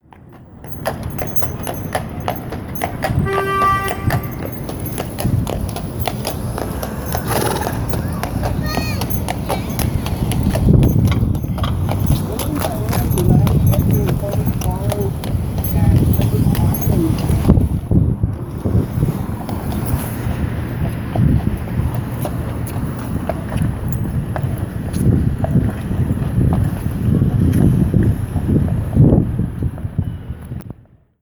We hear sirens, traffic, wind, the sound of falling water, people talking and the call of a talkative sea lion.
Queens Zoo, NYC
Posted in Field Recording | Tagged city , Queens , sea lion , wind , Zoo | Leave a comment |